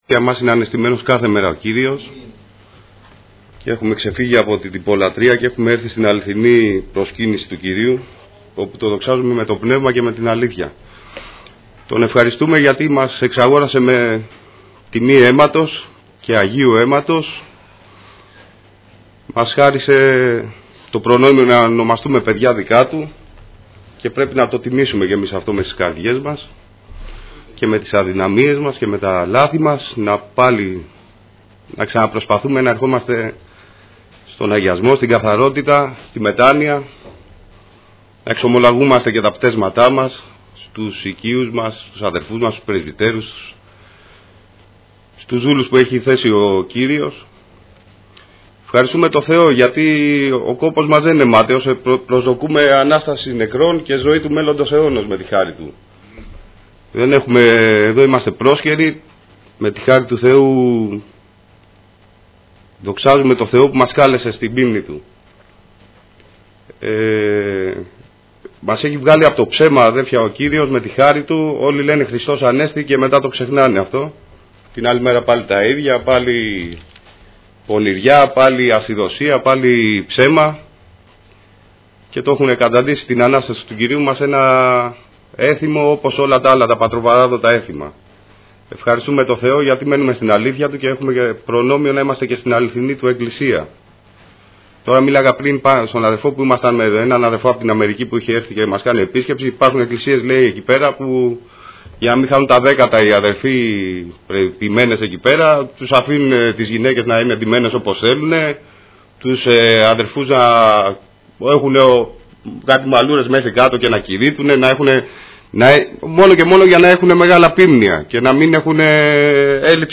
Διάφοροι Ομιλητές Ομιλητής: Διάφοροι Ομιλητές Λεπτομέρειες Σειρά: Κηρύγματα Ημερομηνία: Δευτέρα, 13 Απριλίου 2015 Εμφανίσεις: 508 Γραφή: Ιωάννης 14:25; Προς Εβραίους 9:24-9:28 Λήψη ήχου Λήψη βίντεο